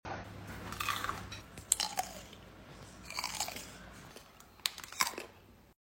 Sounds Like An Apple Fr Sound Effects Free Download